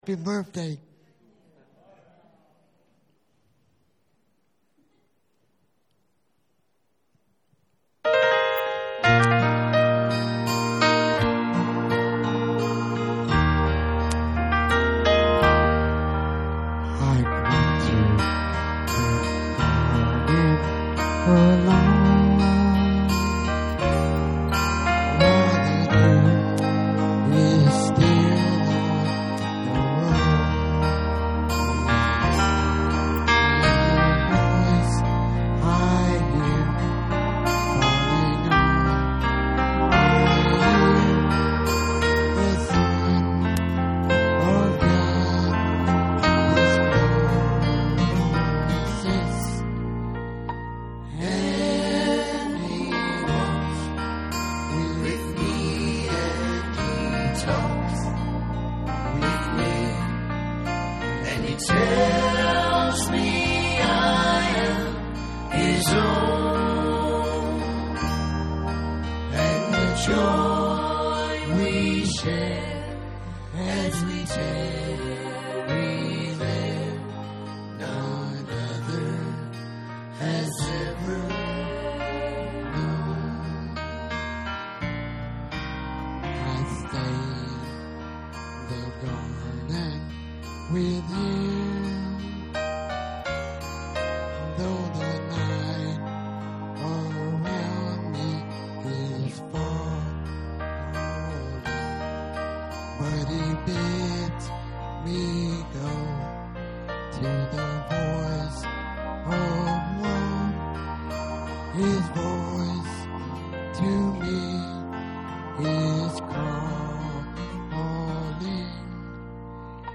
Special Music - Calvary Baptist Church